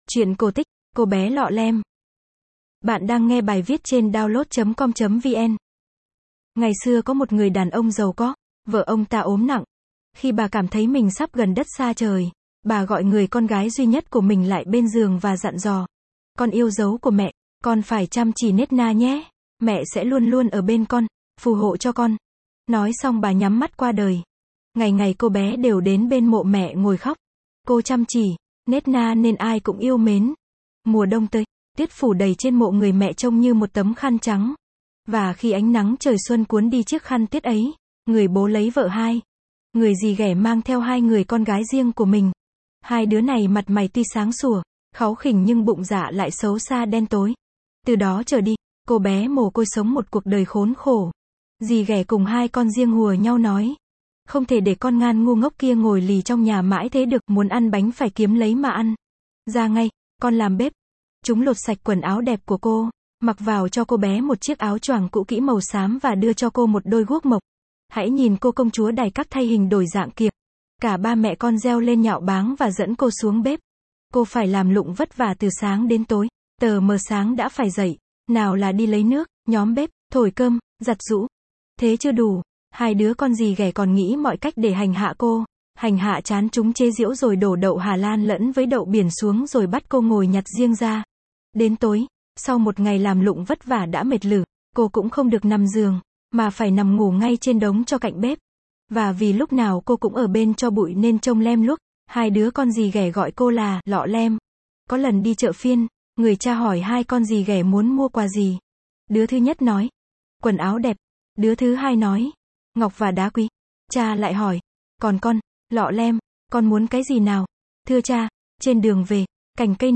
Sách nói | Cô bé Lọ Lem